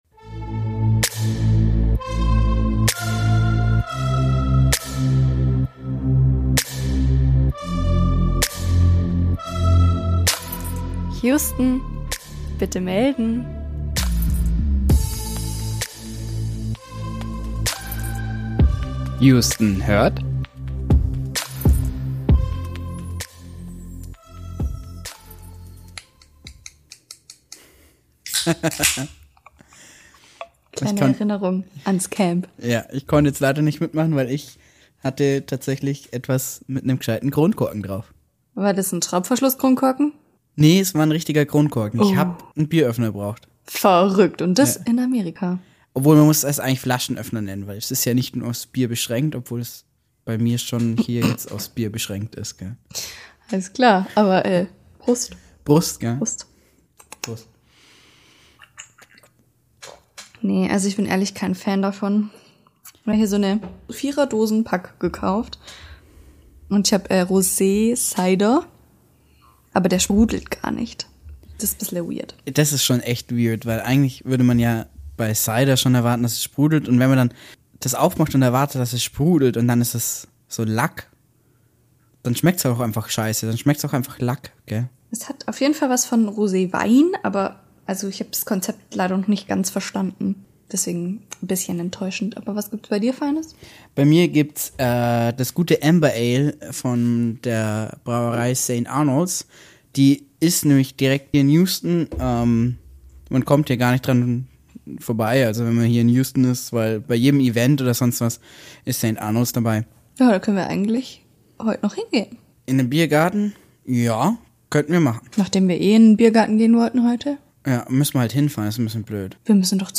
Unser Rückblick auf 2 Wochen Italien und Amerika, live aufgenommen in Houston.